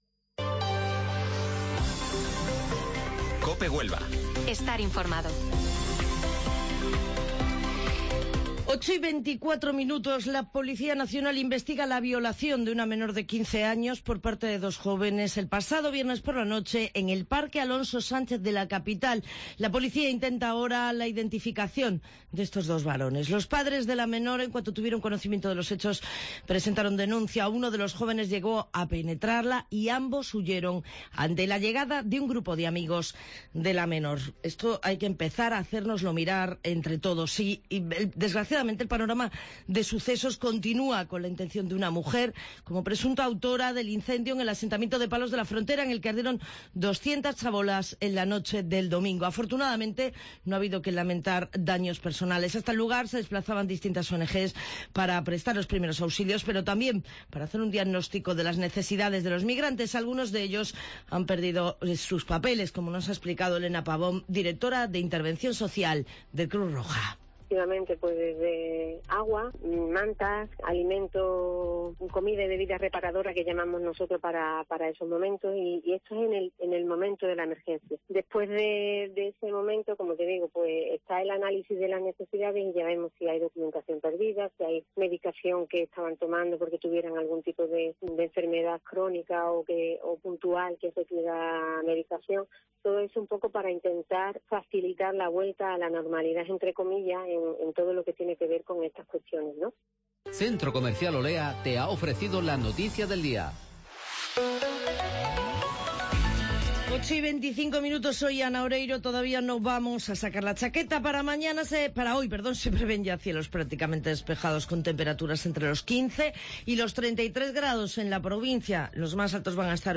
Informativo Matinal Herrera en COPE 26 de septiembre